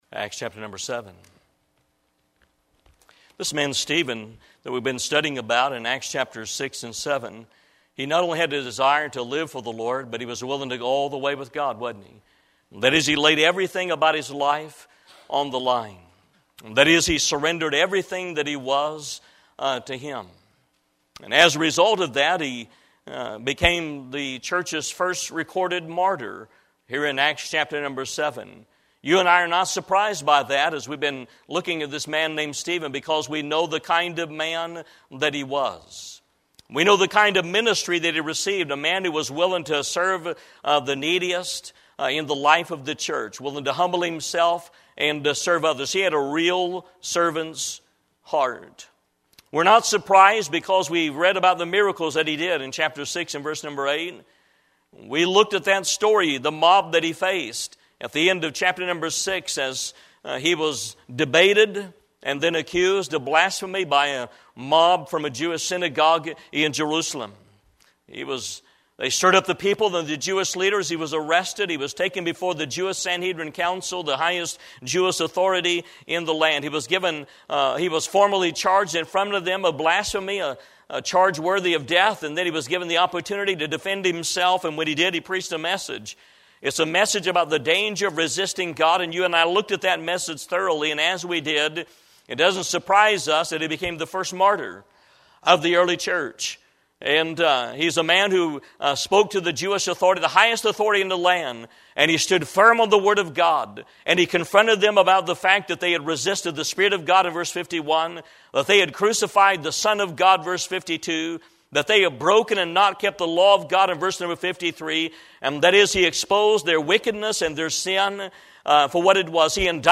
Eastern Gate Baptist Church - The Story of Stephen 38